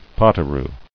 [pot·o·roo]